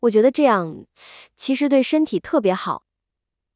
在副语言建模方面，团队推出的合成技术实现了声学模型对自然表达中出现的吸气、笑声、犹豫、修正等多种副语言现象建模，并且结合文本的语义信息自动插入副语言现象。